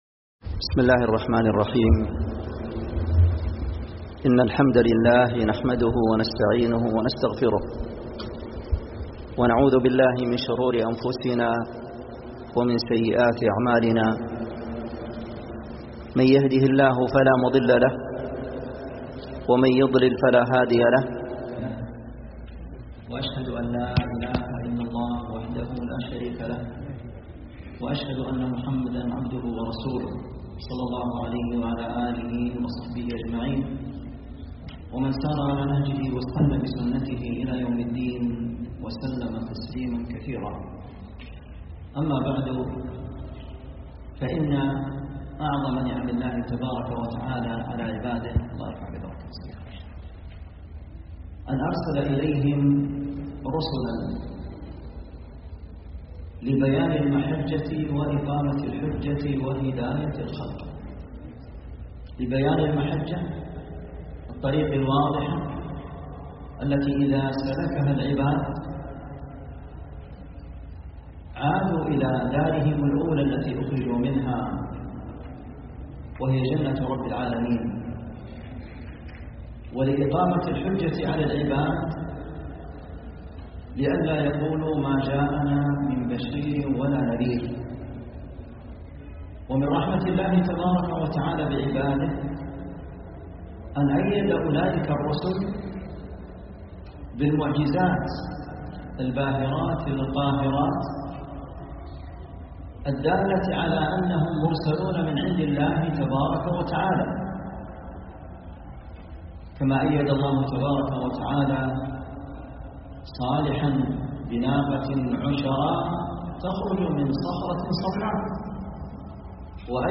محاضرة
جامع الإخلاص - الروضة